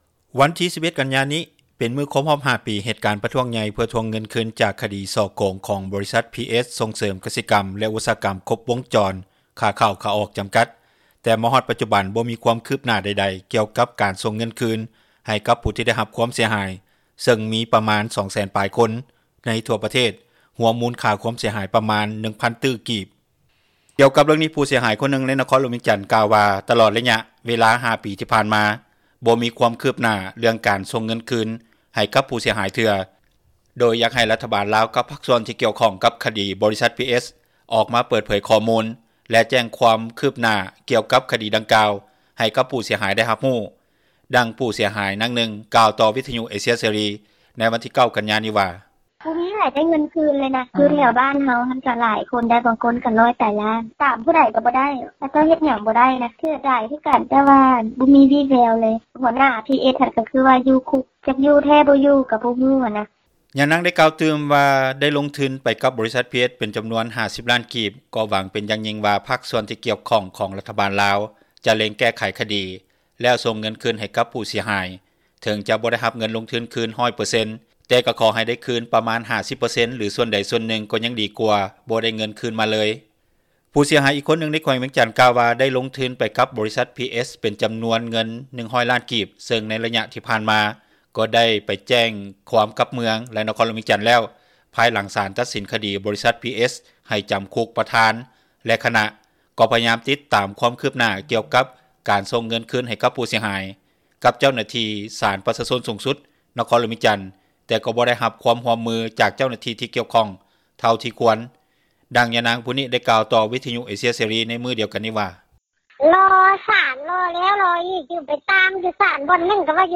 ດັ່ງຜູ້ເສັຽຫາຍນາງນຶ່ງ ກ່າວຕໍ່ວິທຍຸເອເຊັຽເສຣີ ໃນມື້ວັນທີ 9 ກັນຍາ ນີ້ວ່າ:
ດັ່ງຍານາງຜູ້ນີ້ ກ່າວຕໍ່ວິທຍຸເອເຊັຽເສຣີ ໃນມື້ດຽວກັນນີ້ວ່າ: